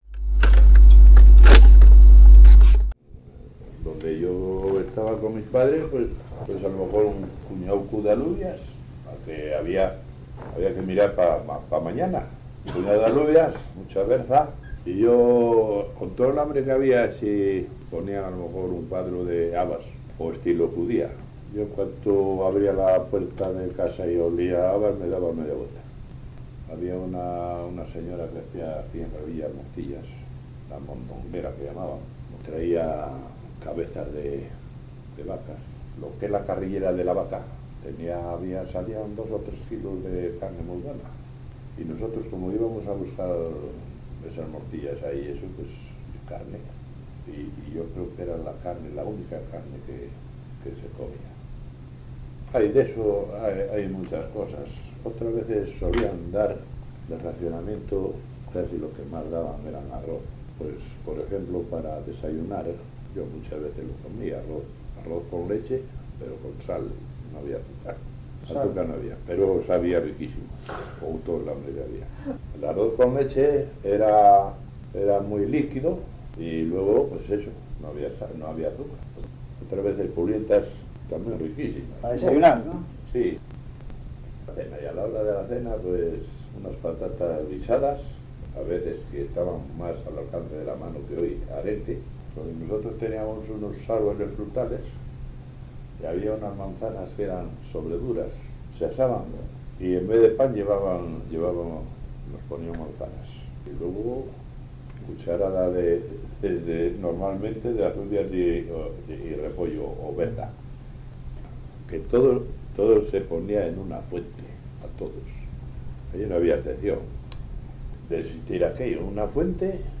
Historia oral del valle de Camargo